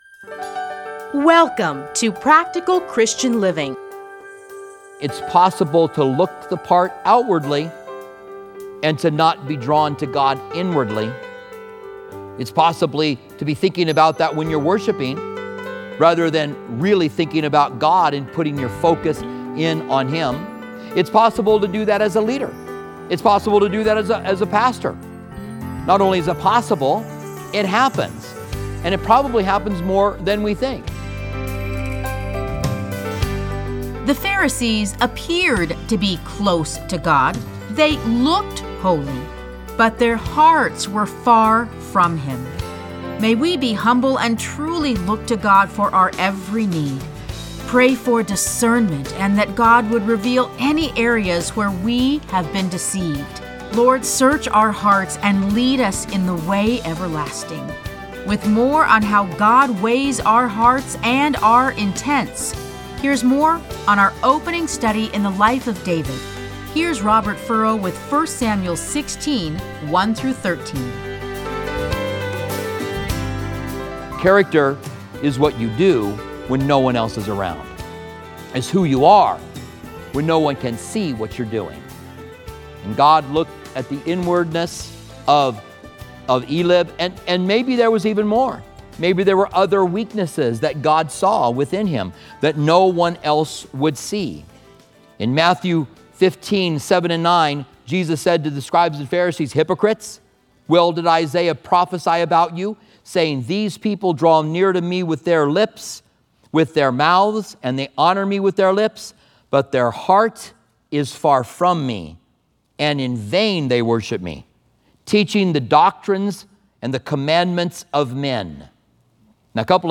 Listen to a teaching from 1 Samuel 16:1-13.